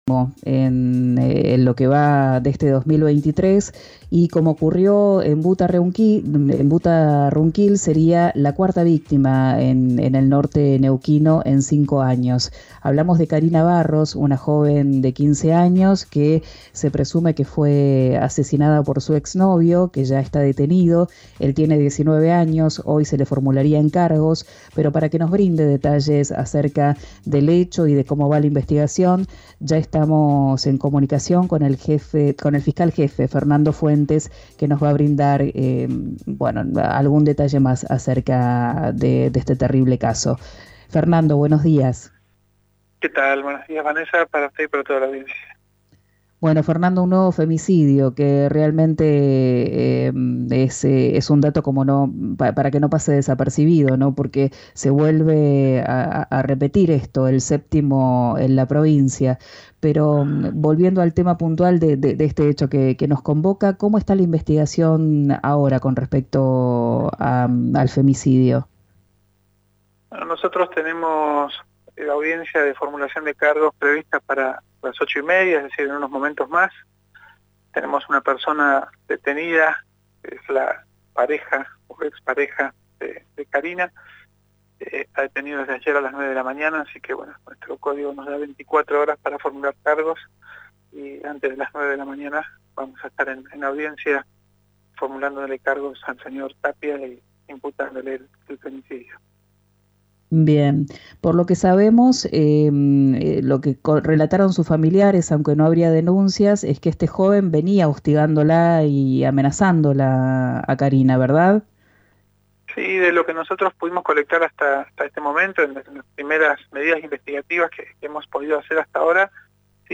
El fiscal jefe, Fernando Fuentes, contó en RÍO NEGRO RADIO que se estaba dragando una laguna para tratar de dar con el arma homicida.